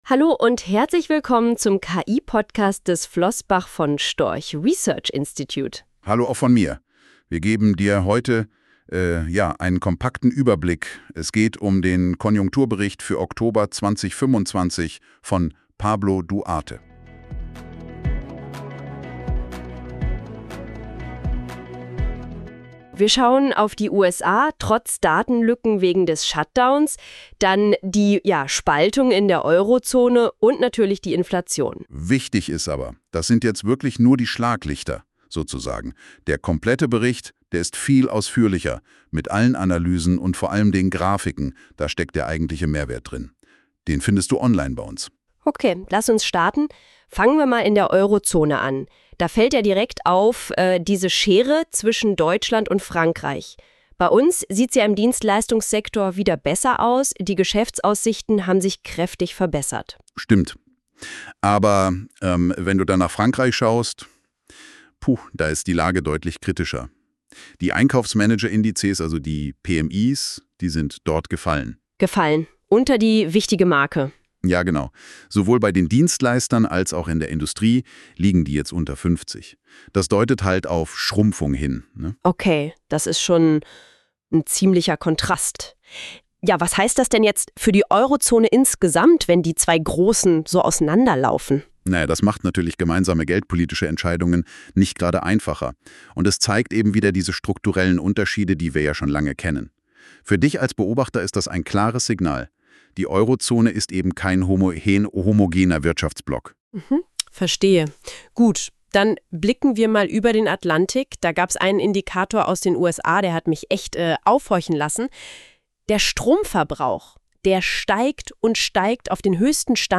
KI-generierten Podcast  (generiert durch NotebookLM) die wichtigsten Punkte des Konjunkturberichts diskutiert.